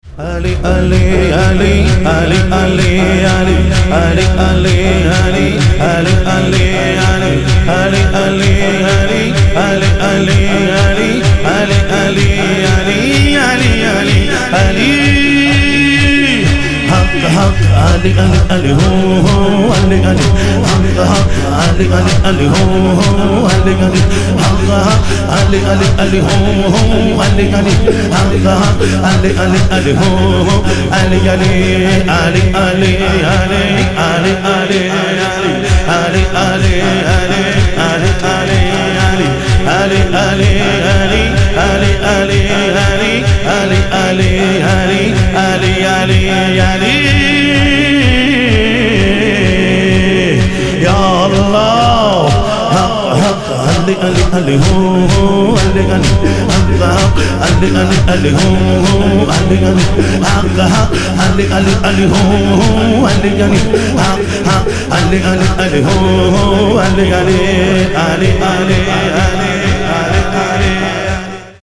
شور
شب شهادت امام صادق علیه السلام